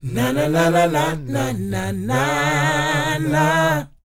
NA-NA D4B -R.wav